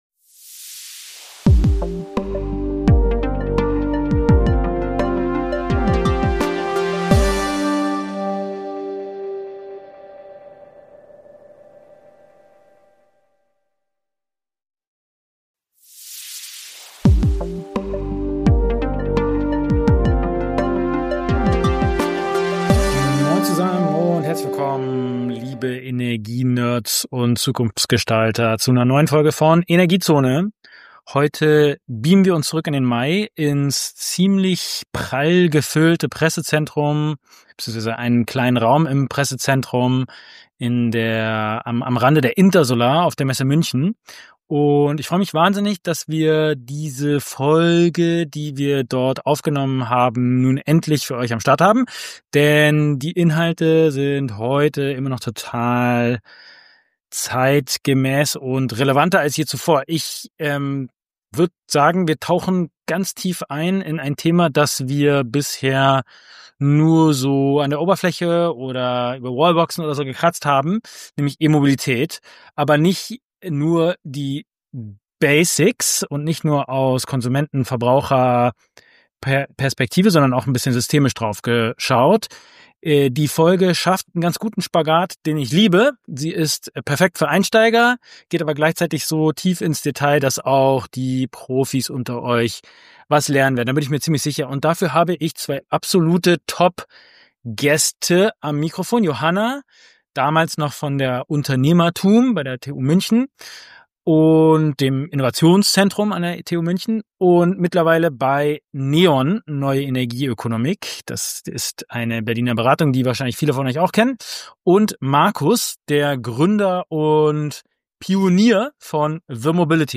Beschreibung vor 8 Monaten In dieser Episode von Energiezone begeben wir uns auf eine spannende Reise in die Welt der Elektromobilität, aufgenommen im Pressezentrum der Intersolar Messe in München.